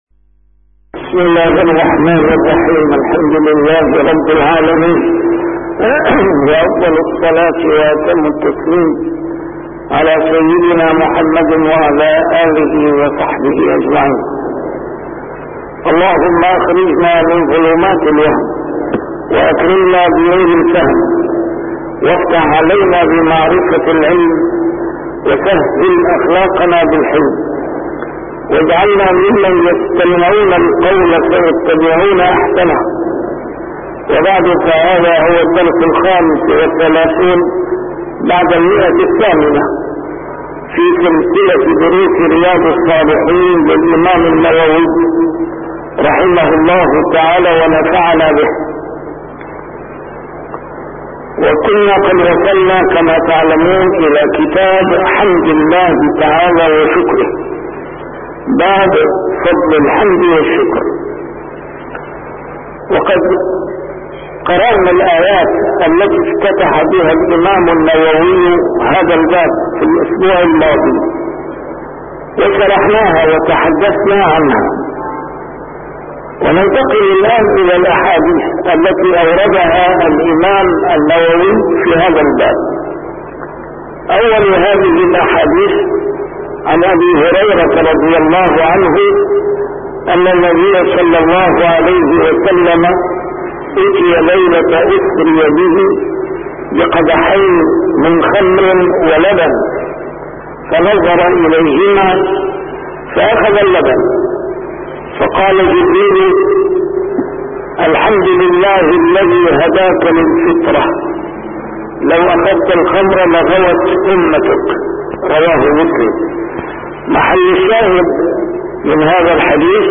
A MARTYR SCHOLAR: IMAM MUHAMMAD SAEED RAMADAN AL-BOUTI - الدروس العلمية - شرح كتاب رياض الصالحين - 835- شرح رياض الصالحين: فضل الحمد والشكر